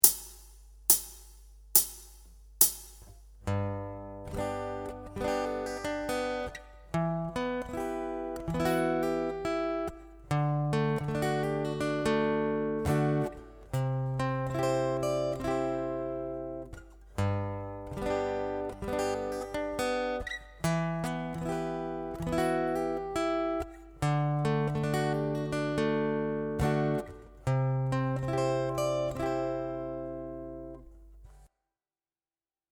Acoustic Rhythm Guitar Chord Embellishment 3
The result is a fantastic sounding arrangement of our chord progression. The new voicing’s of the chord along with the embellishments really make it come to life.